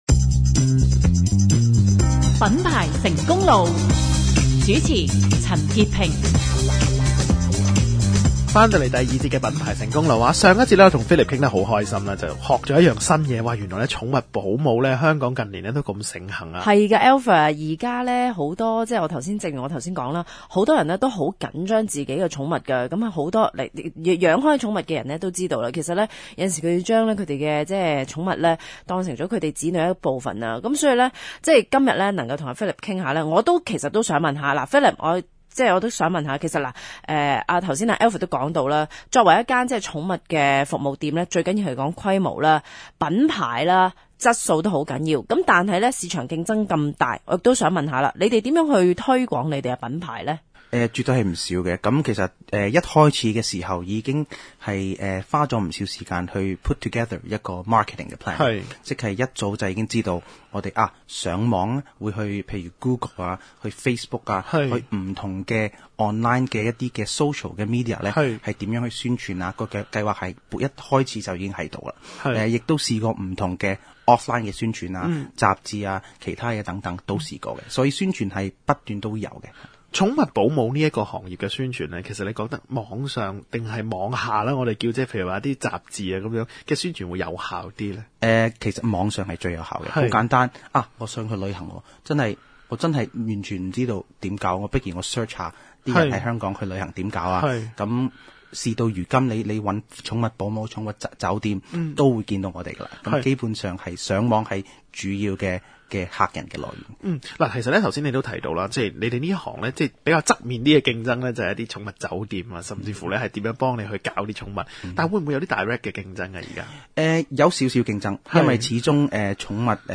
受訪嘉賓：